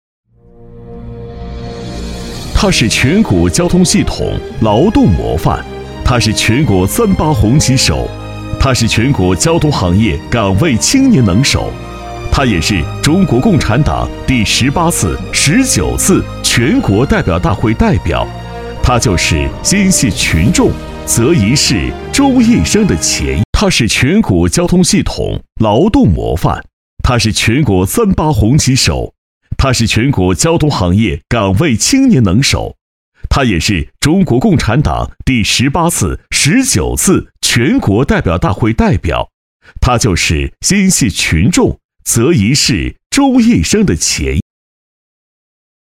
签约技能： 广告 专题
配音风格： 激情，嗓门大
颁奖 劳动模范